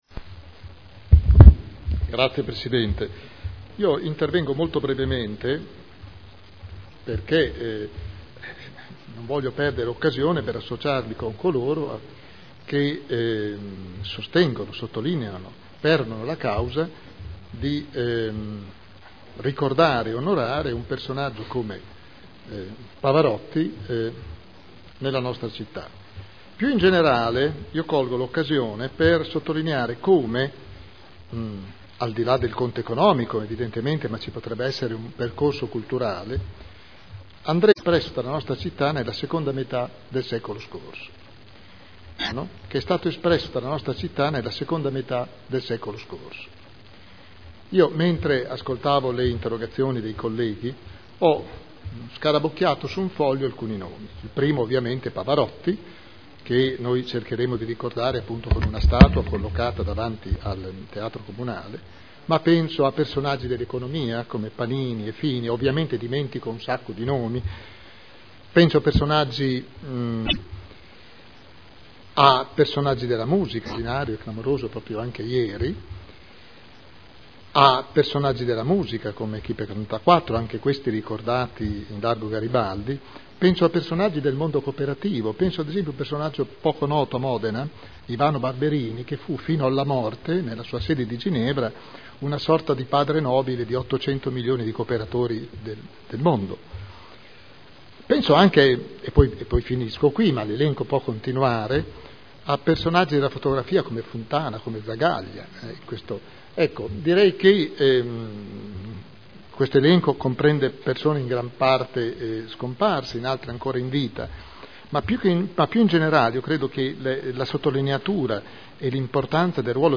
William Garagnani — Sito Audio Consiglio Comunale